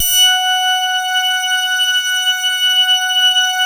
BAND PASS .9.wav